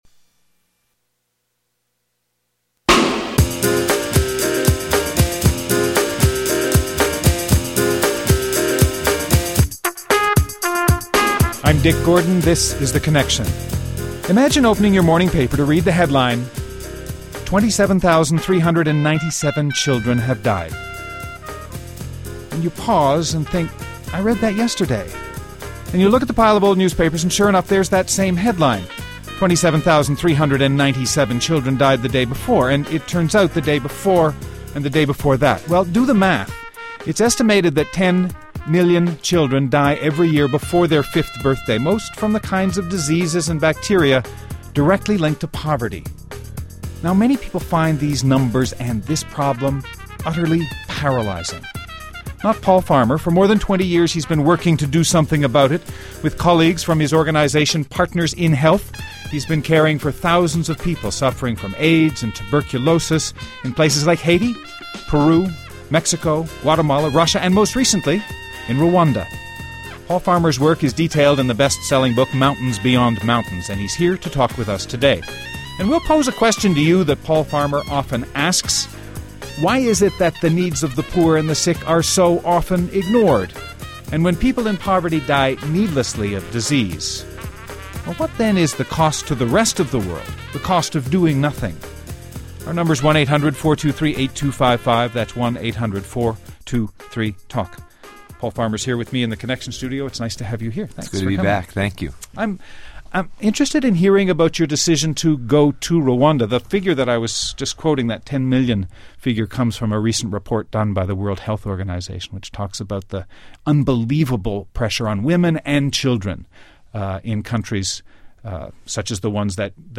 Guests: Paul Farmer, MD, PhD, Professor of Medical Anthropology in the Department of Social Medicine at the Harvard Medical School, attending physician in the Division of Infectious Disease at the Brigham and Women’s Hospital, and medical co-director of the Clinique Bon Sauveur, a charity hospital in rural Haiti.